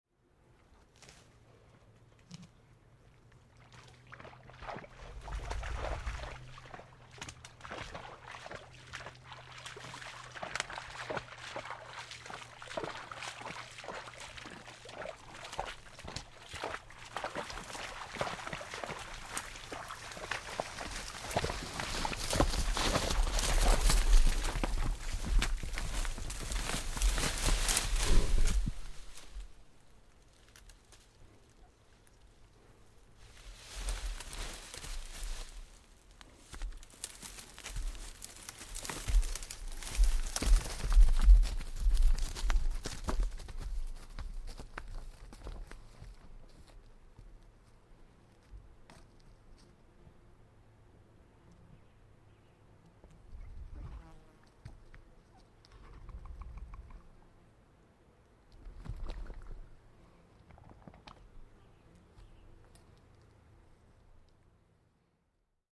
Moose: